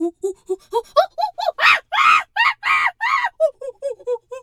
Animal_Impersonations
monkey_2_chatter_scream_07.wav